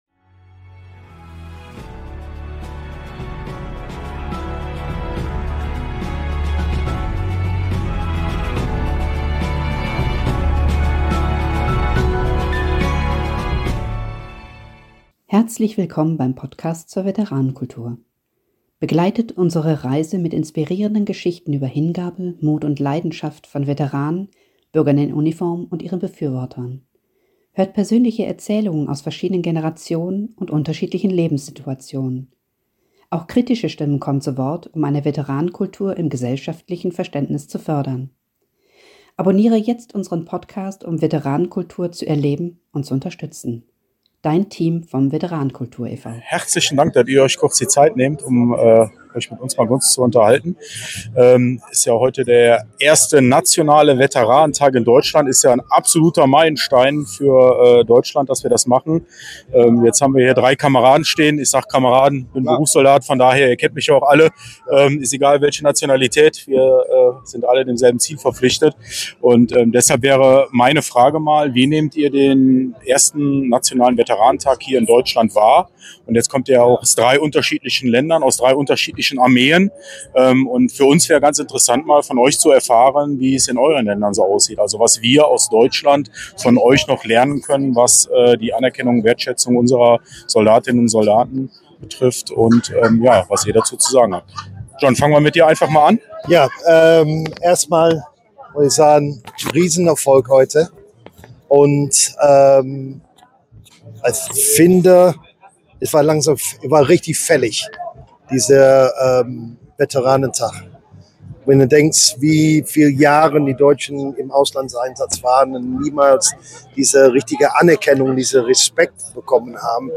Interview in Duisburg